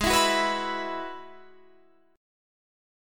Abm7#5 chord